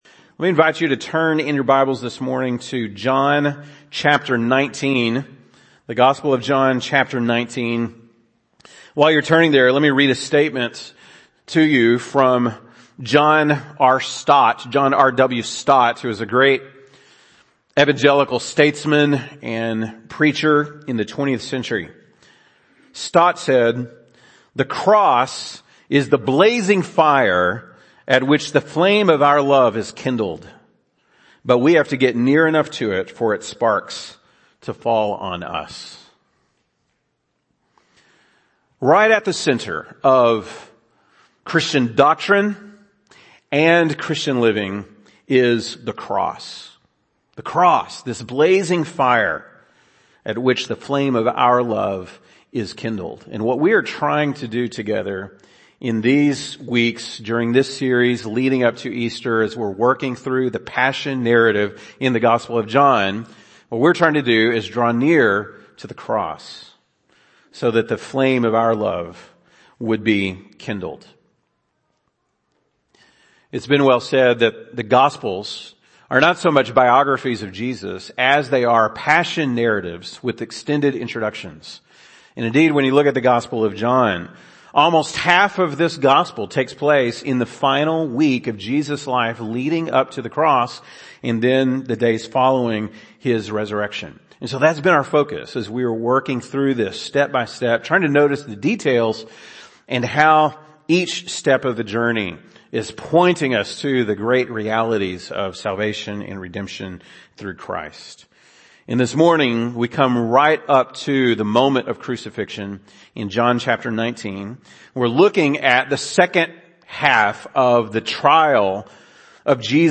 March 27, 2022 (Sunday Morning)